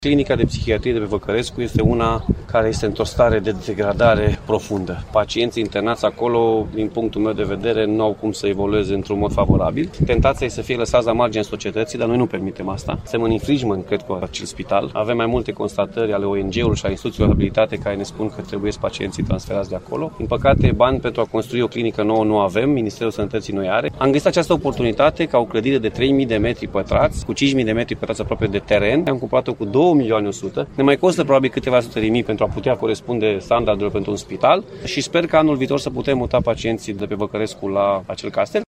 Președintele Consiliului Județean Timiș, Alfred Simonis, spune că era necesar ca pacienții internați la Clinica de Psihiatrie “Eduard Pamfil” să fie mutați.